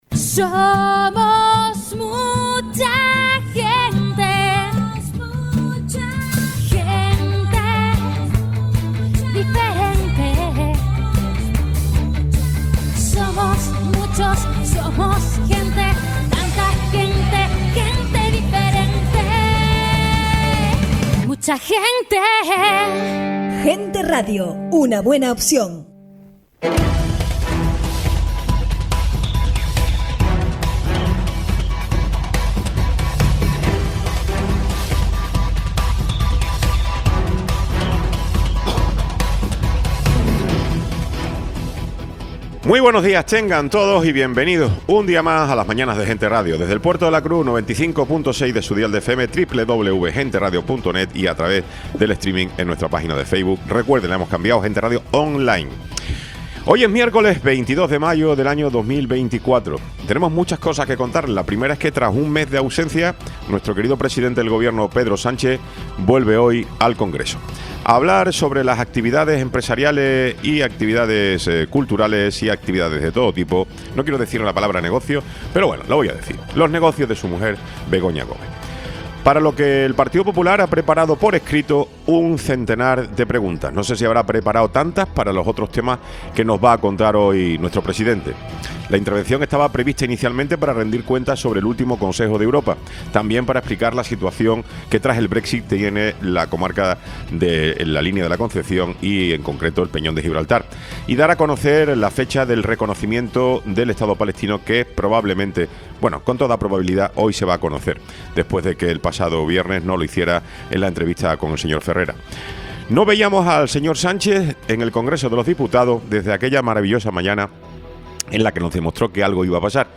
Programa sin cortes